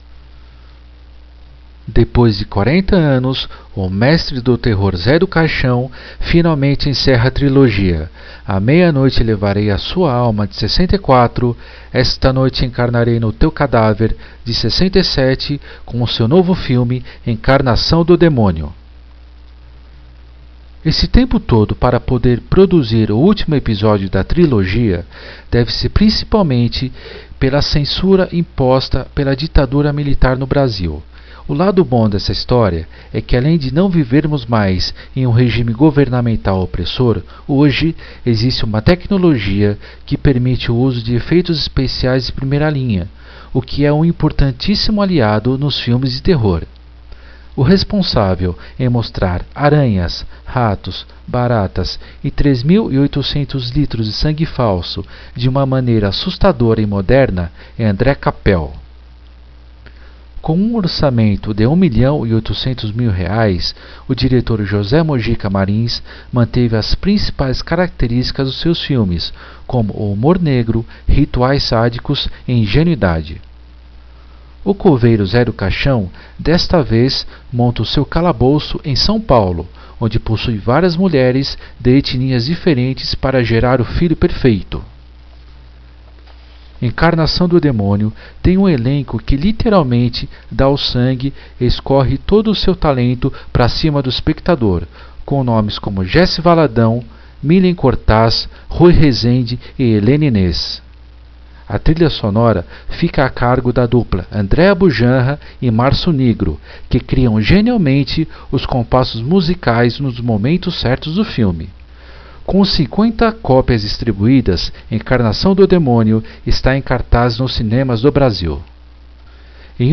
Crítica sonorizada no site Noorádio: